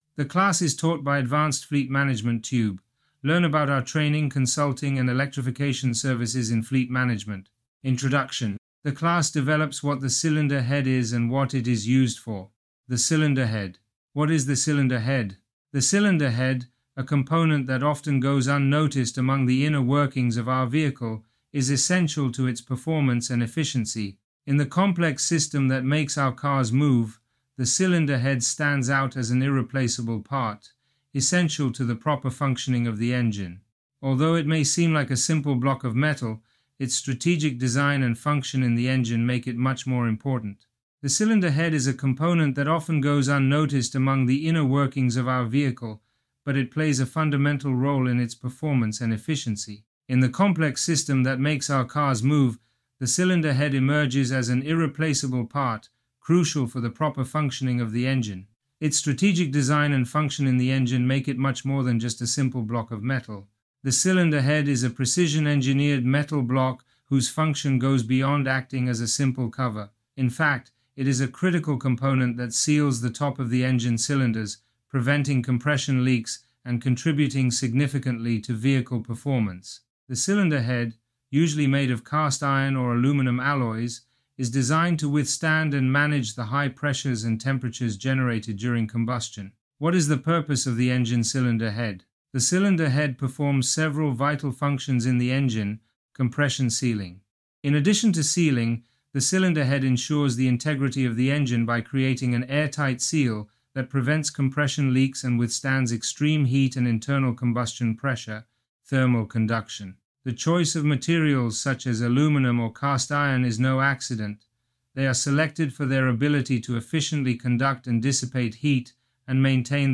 Online class